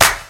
Claps
Grindin' Clap.wav